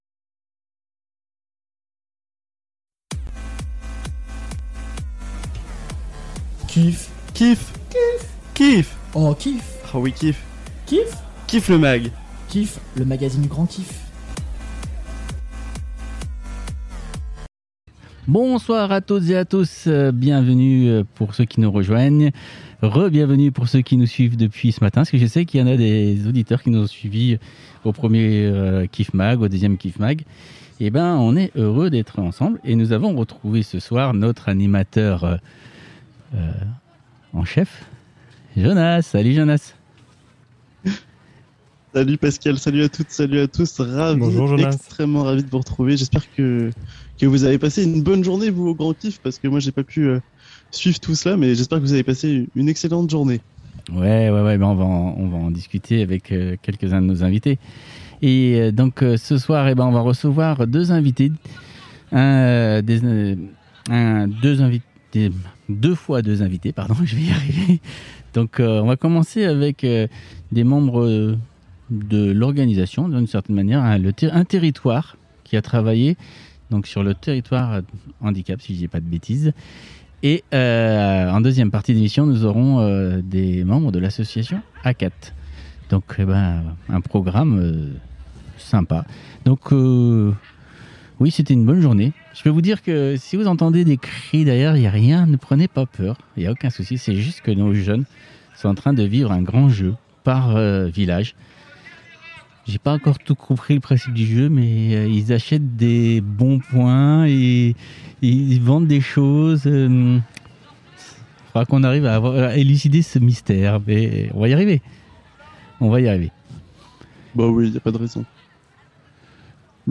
Le 4ème numéro du KIFFMAG en direct de ALBI le 30/07/2021 à 18h00